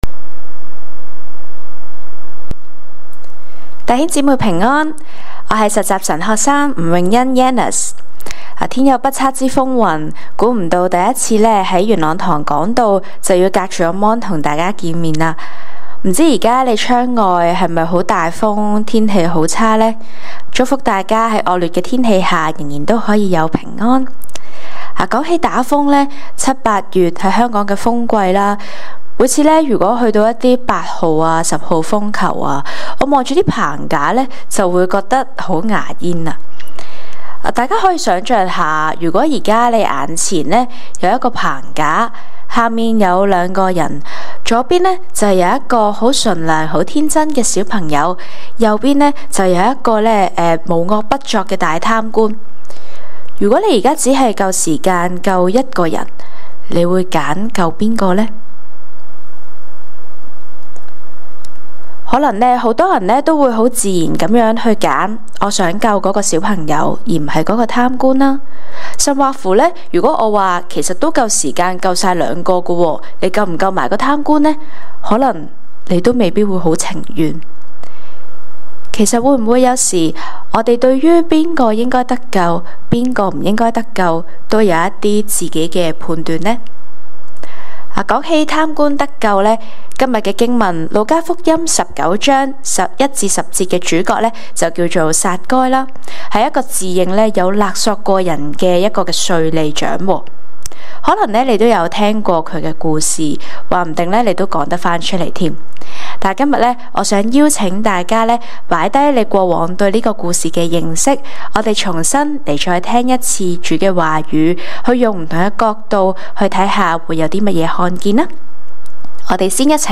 證道集
恩福元朗堂崇拜-早、中堂